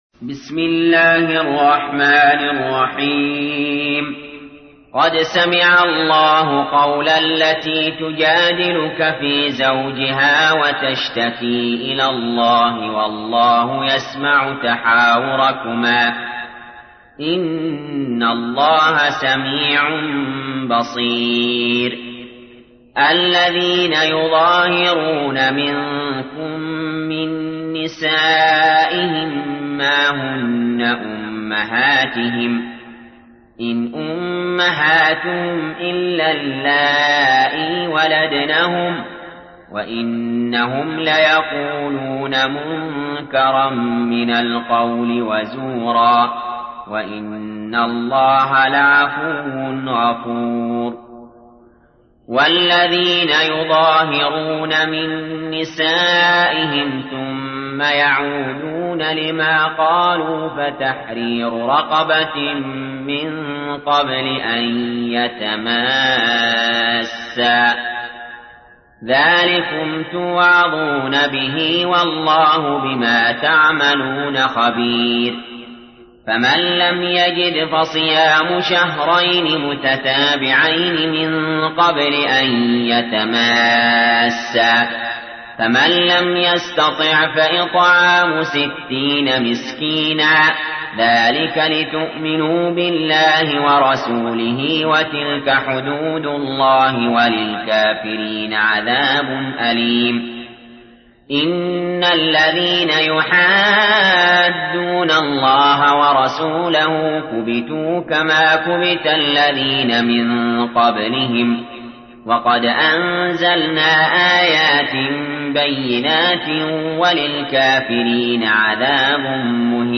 تحميل : 58. سورة المجادلة / القارئ علي جابر / القرآن الكريم / موقع يا حسين